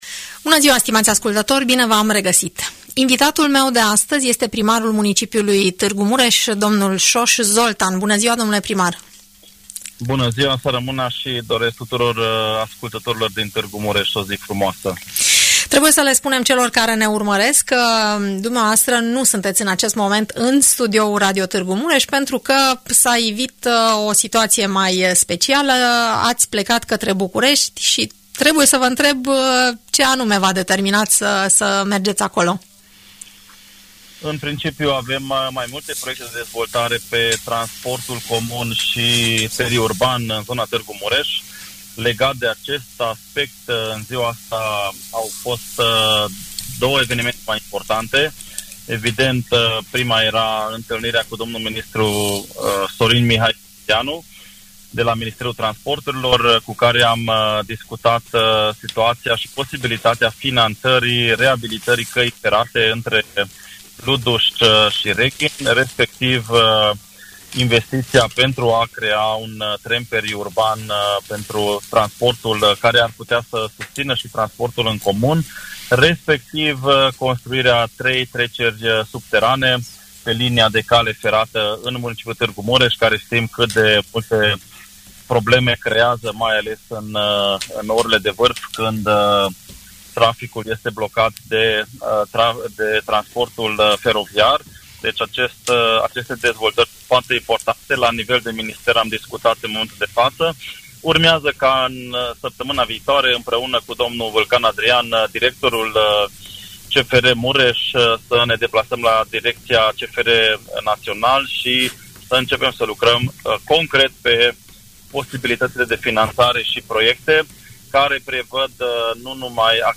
Primarul Tg. Mureșului, în direct la Radio!